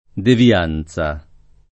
devianza [ devi- # n Z a ] s. f.